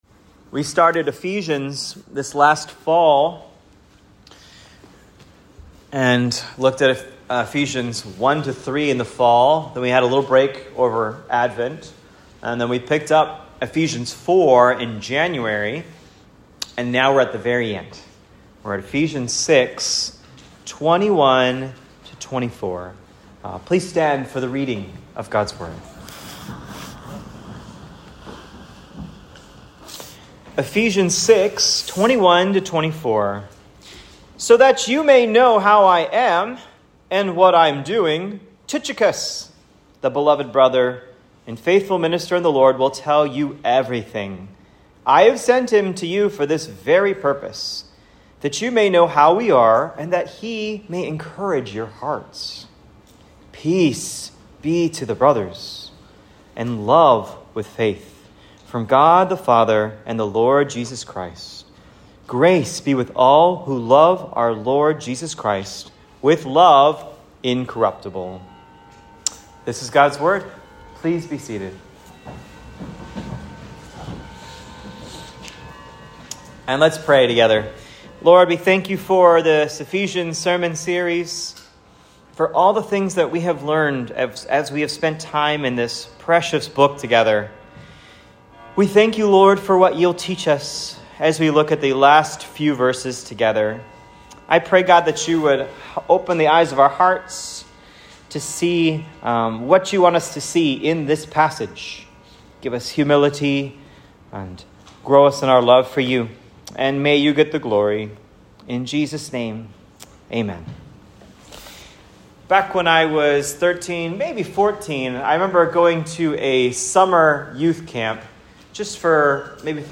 Love incorruptible: Ephesians 6:21-24 sermon.